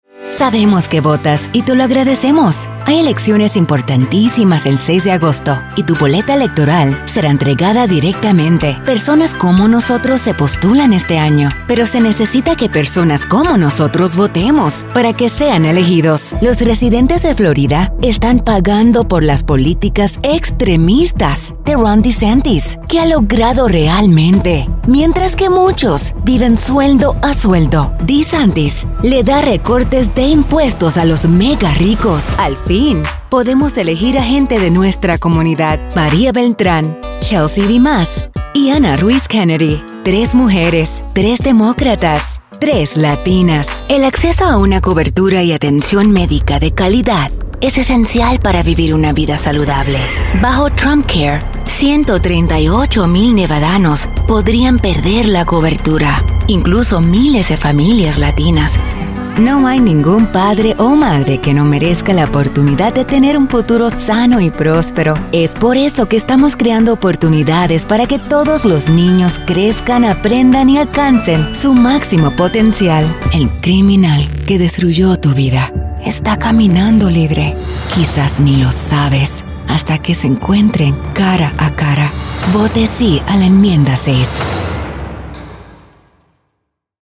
announcer, anti-announcer, attitude, authoritative, concerned, confessional, confident, conversational, foreign-language, genuine, Gravitas, high-energy, informative, inspirational, middle-age, motivational, narrative, political, spanish-dialect, spanish-showcase, thoughtful, tough, upbeat